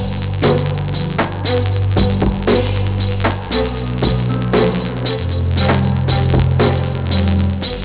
It’s a slow marcher, with a very royal and regal flavor.